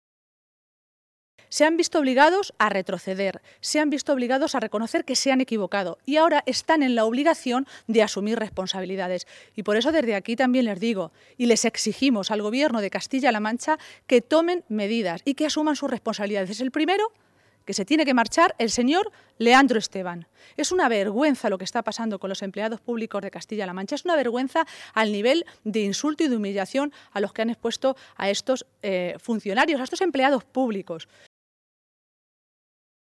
Maestre se pronunciaba de esta manera esta tarde, en Manzanares, en el Castillo de Pilas Bonas, minutos antes de que comenzara la reunión de la ejecutiva regional socialista.
Cortes de audio de la rueda de prensa